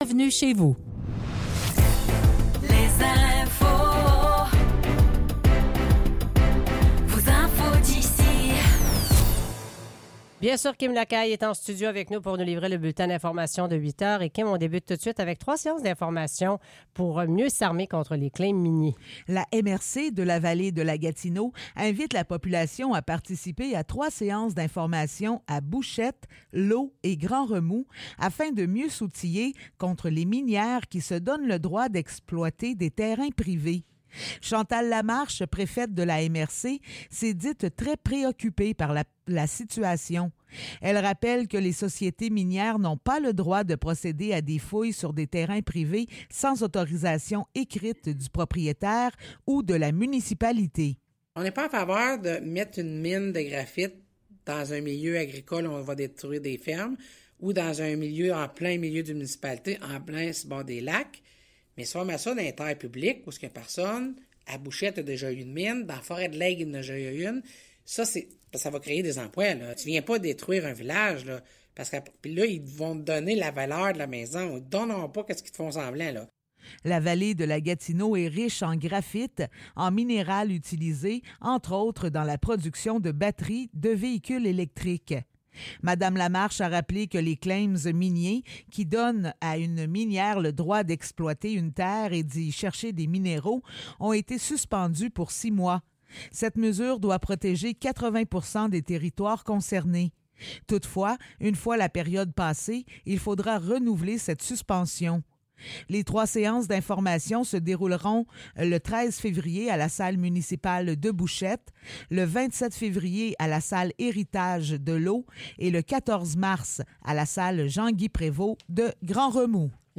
Nouvelles locales - 9 février 2024 - 8 h